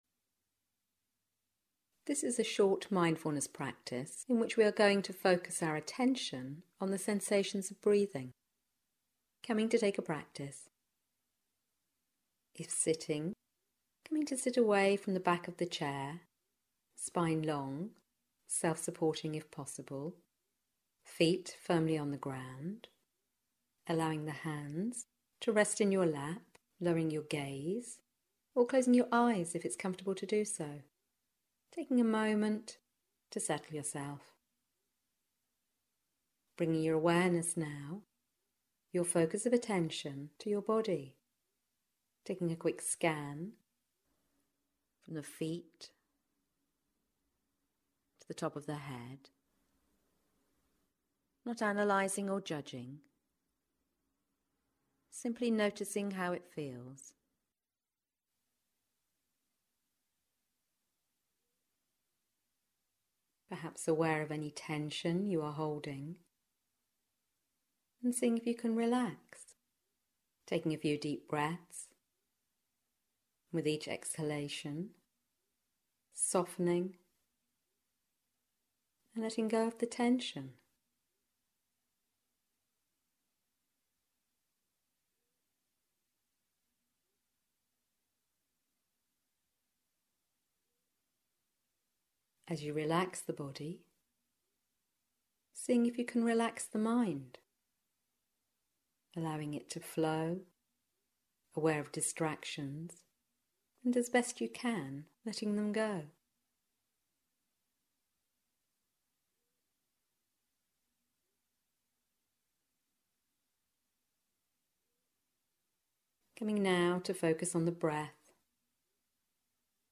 Short Mindfulness Practice (7 minutes)
Short-mindfulness-Practice-7-mins-2.mp3